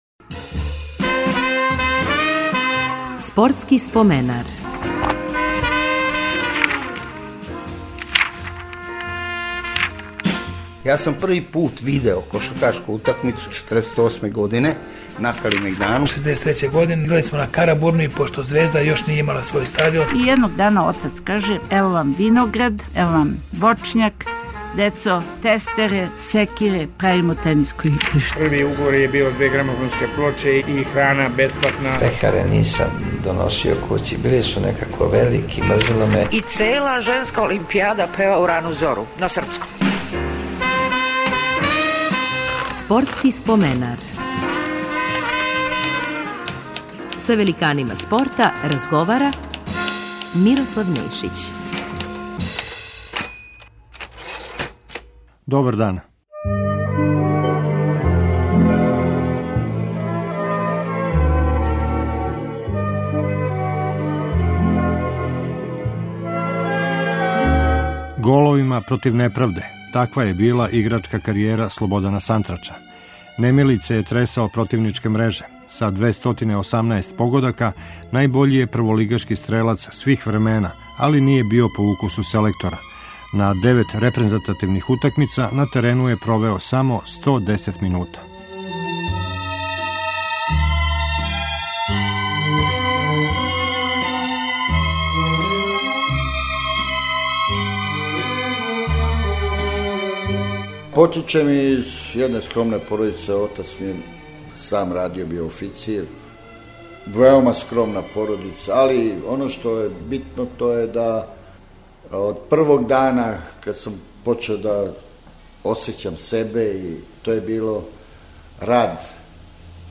Гост емисије биће фудбалер Слободан Сантрач. Играјући за ОФК Београд, Партизан и Галенику постигао је 218 првенствених голова и тако постао наш најбољи голгетер свих времена.